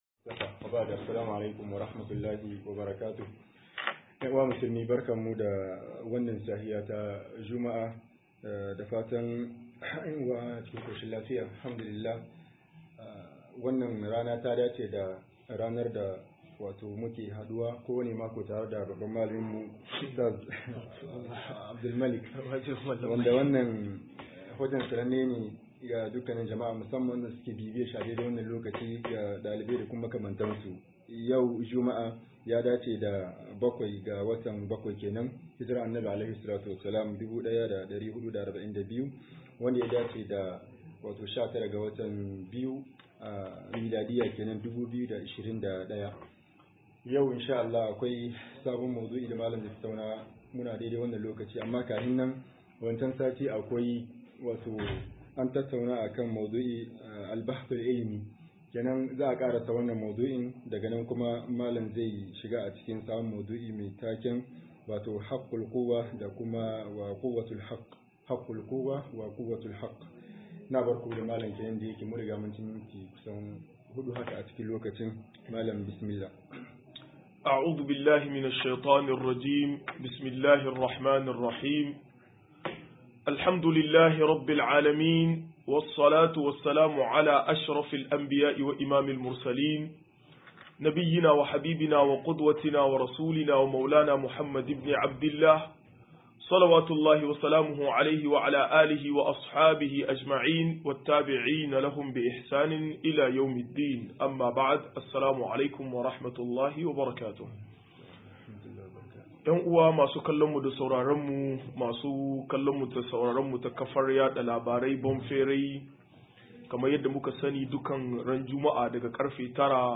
98-Jardin Gaskiya - MUHADARA